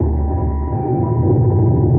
sonarPingSuitVeryCloseShuttle3.ogg